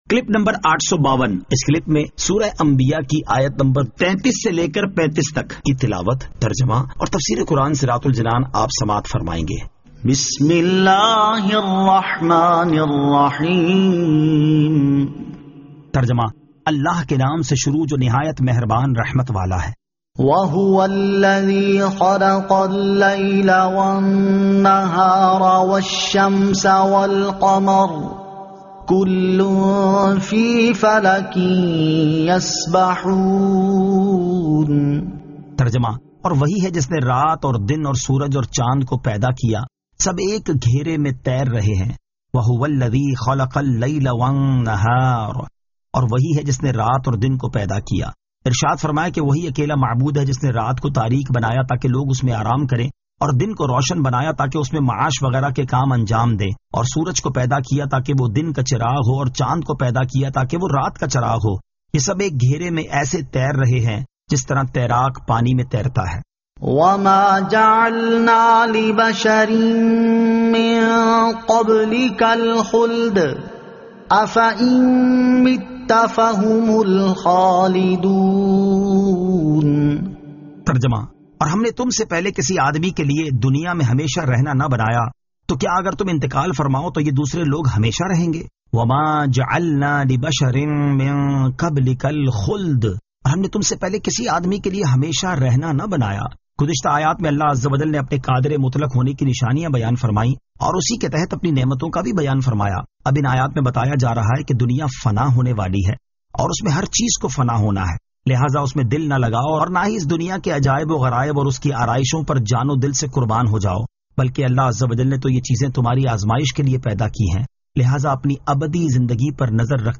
Surah Al-Anbiya 33 To 35 Tilawat , Tarjama , Tafseer